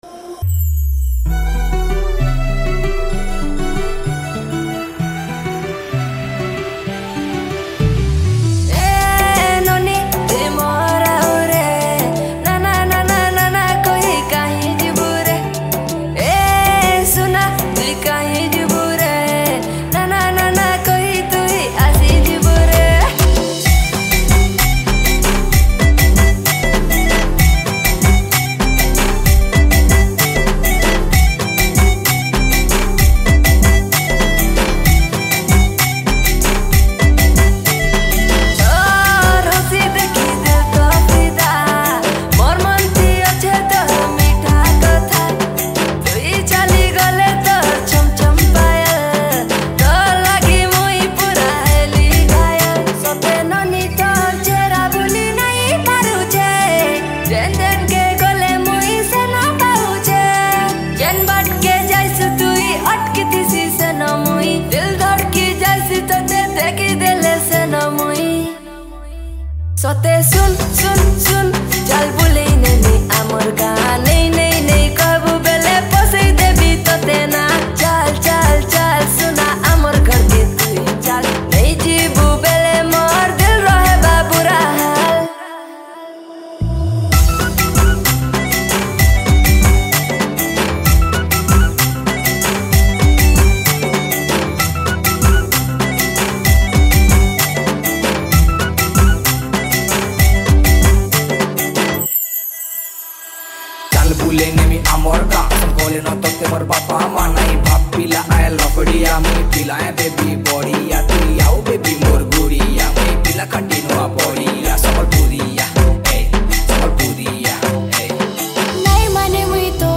Category: New Sambalpuri Songs 2022